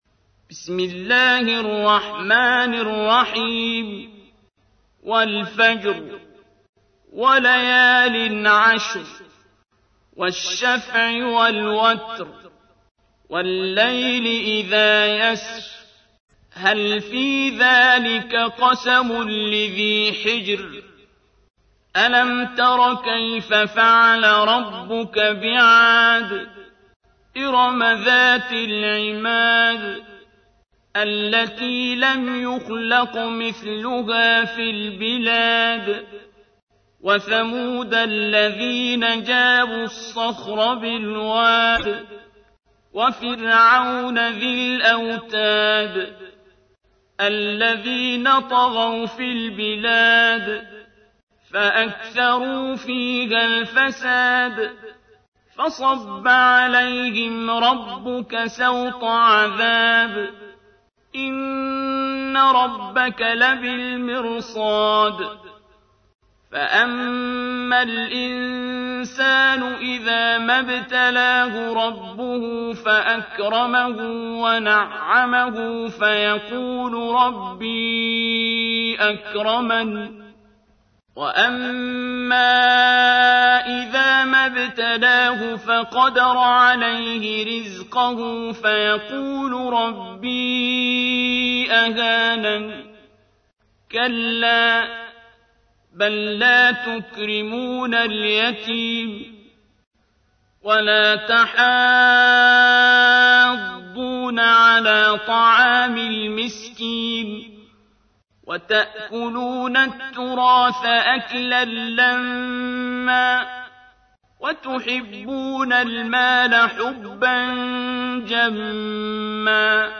تحميل : 89. سورة الفجر / القارئ عبد الباسط عبد الصمد / القرآن الكريم / موقع يا حسين